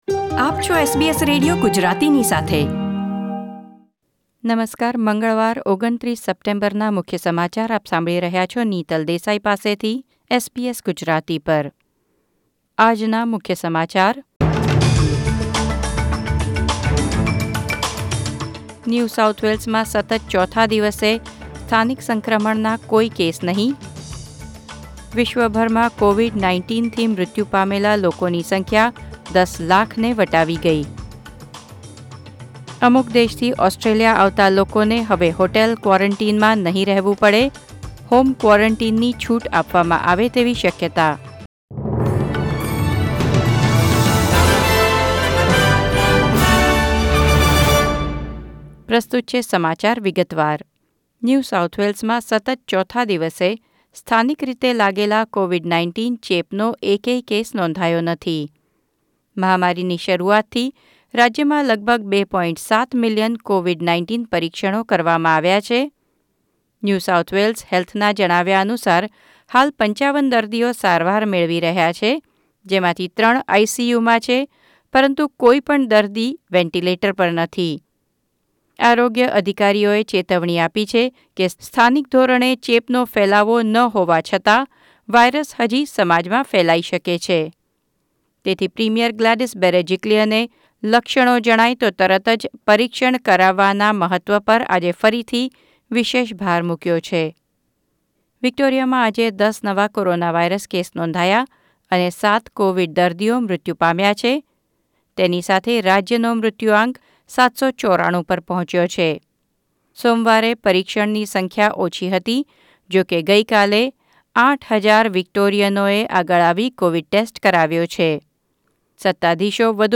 SBS Gujarati News Bulletin 29 September 2020